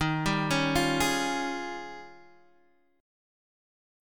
D#11 Chord
Listen to D#11 strummed